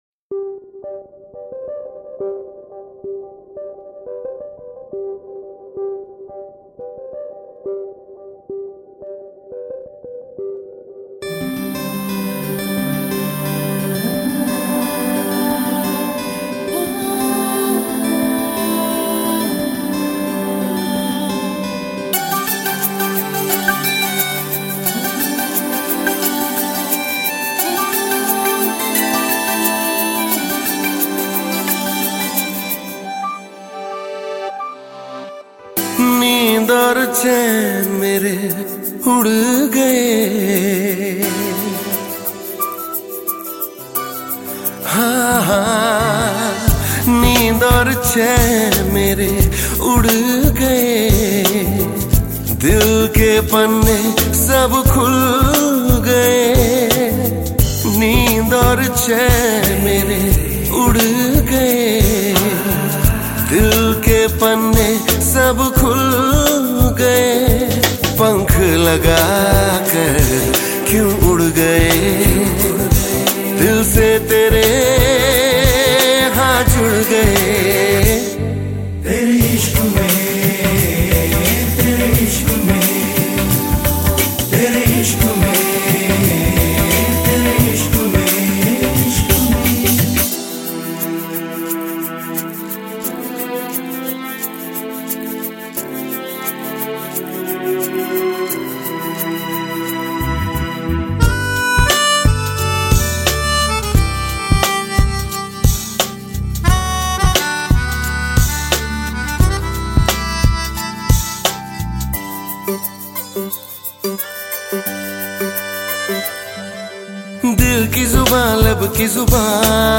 Bollywood Mp3 Music